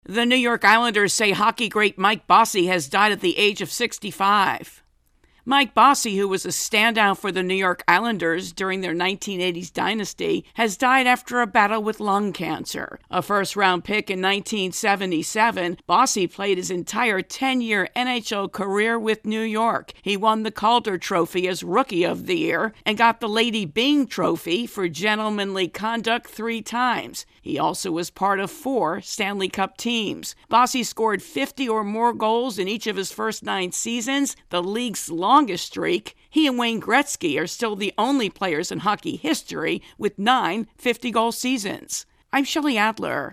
Bossy intro and voicer